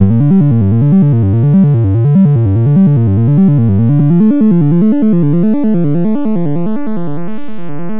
Molecule Man F# 120.wav